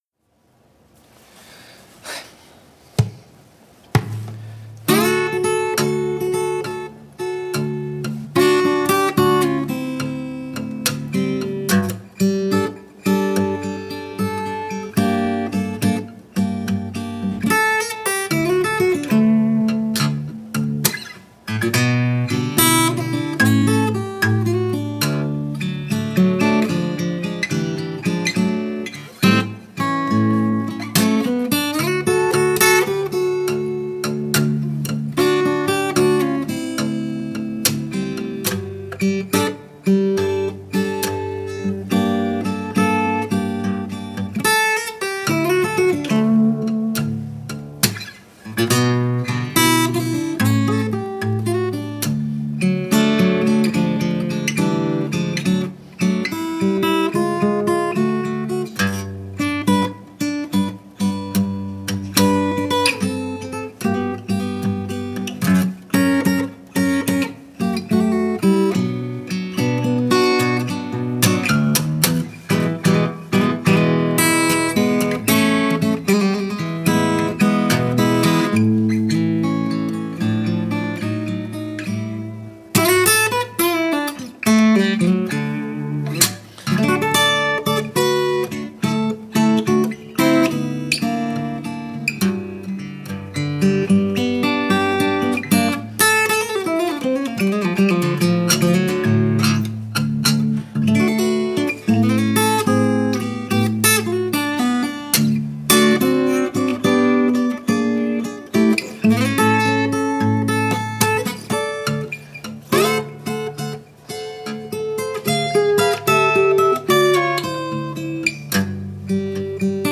I don't really remember the motivation but I think it had something to do with using some classical techniques with the blues. Before you ask, yes it's just one guitar. No, it's not mixed with a 2nd guitar.
It's D major, if you're curious.
only thing I didn't like is it sounded like it continued on when it faded out and ended.